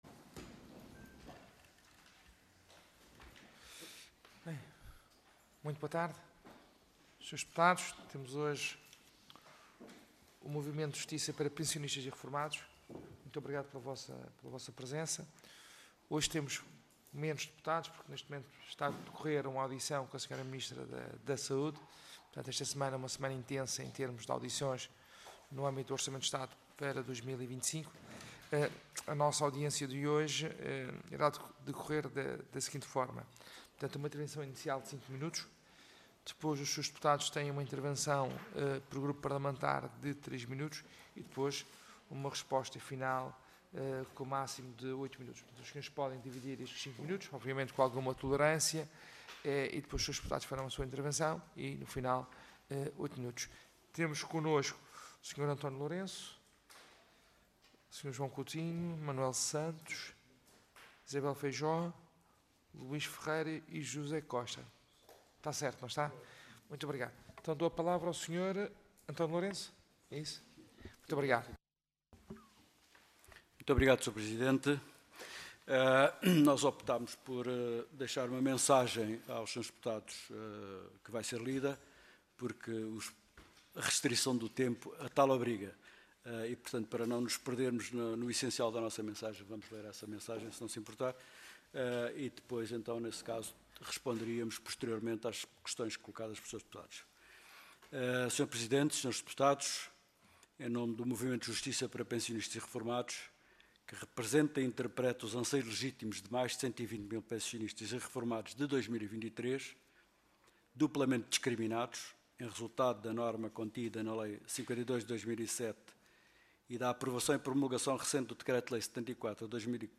Comissão de Orçamento, Finanças e Administração Pública Audiência Parlamentar